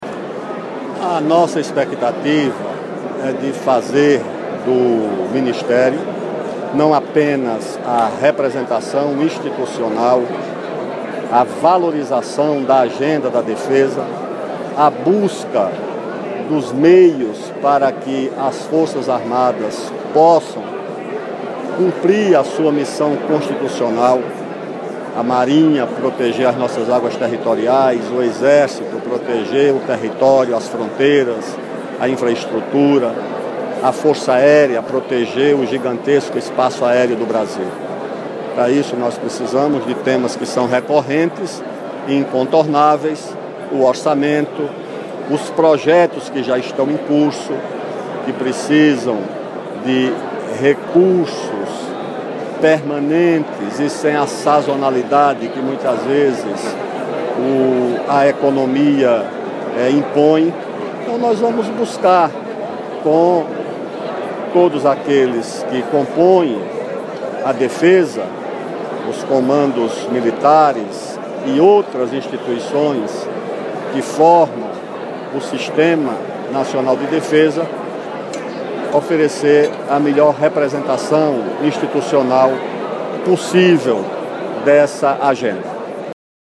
Rebelo salientou que, com os comandos militares e outras instituições que formam o sistema de Defesa, buscará oferecer o melhor apoio possível para o cumprimento de uma agenda que inclui a manutenção dos programas estratégicos na área. Ouça a primeira declaração do ministro da Defesa após ser empossado no cargo .
ministroa_aldoa_entrevista.mp3